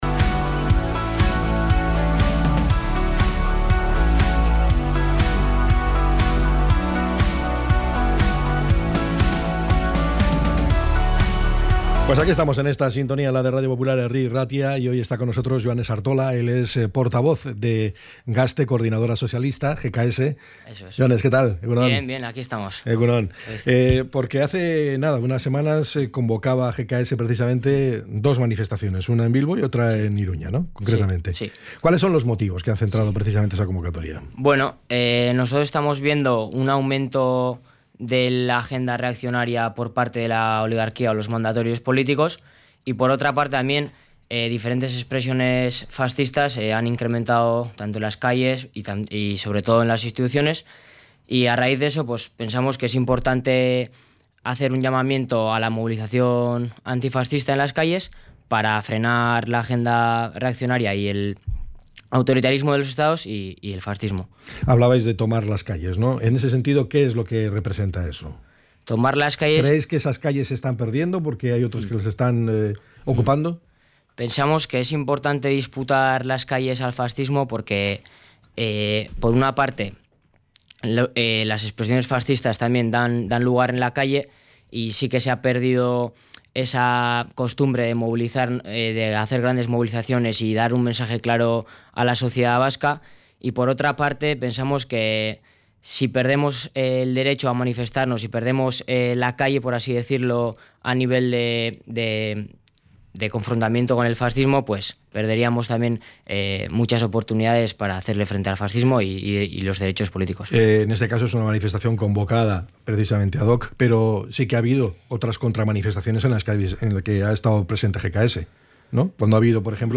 ENTREV.-GKS.mp3